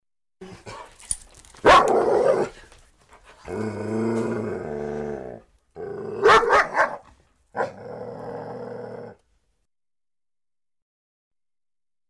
Category: dog sound effect